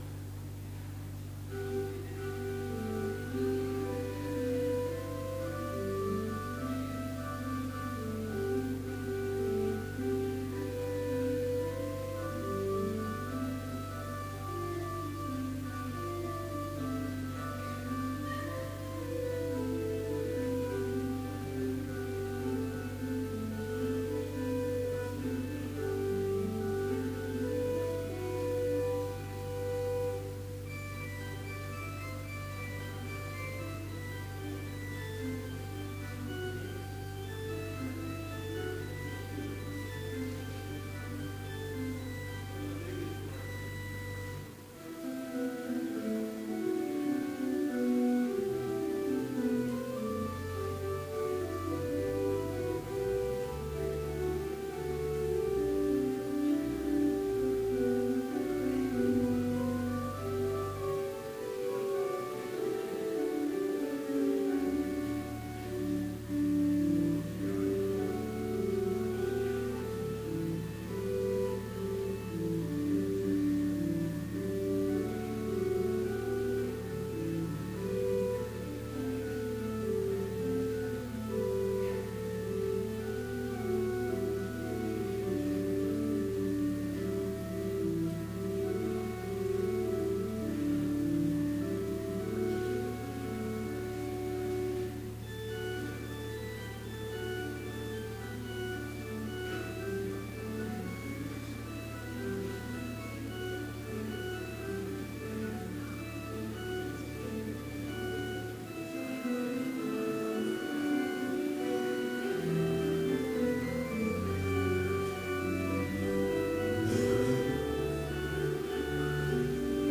Complete service audio for Chapel - December 13, 2016